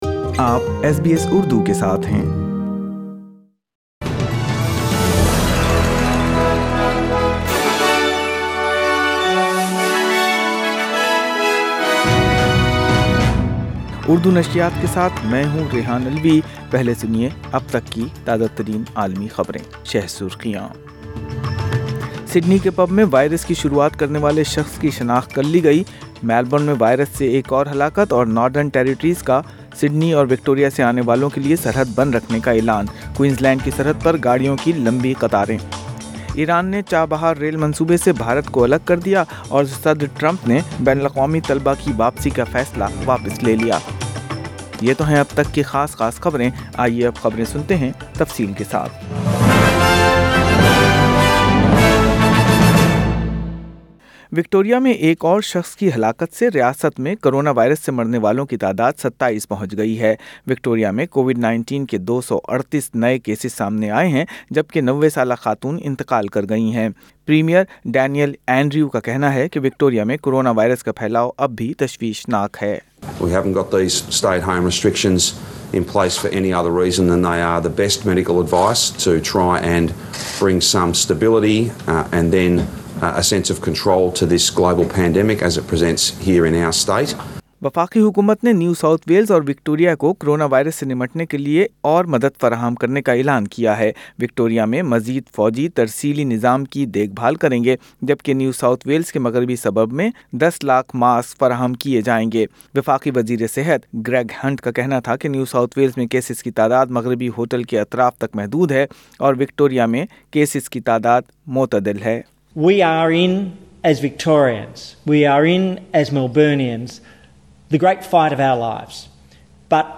اردو خبریں 15 جولائی 2020